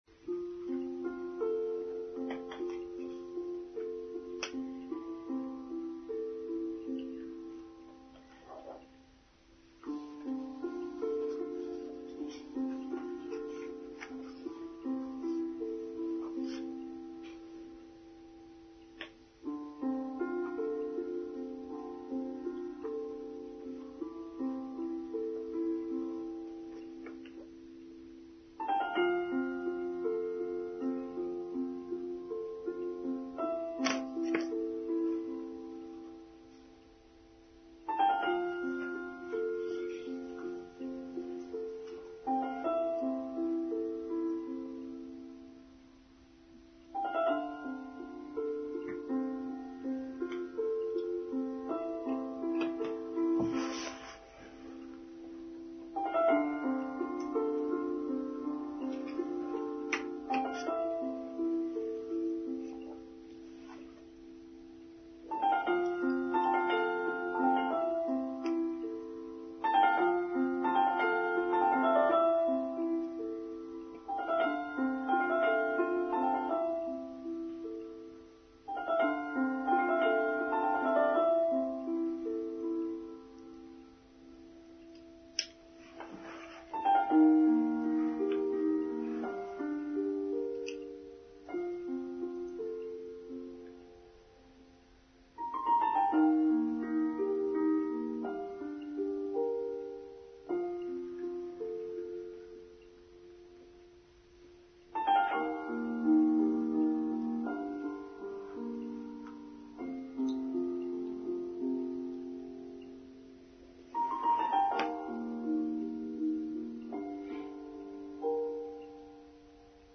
What’s in the Temple? Online Service for Sunday 19th May 2024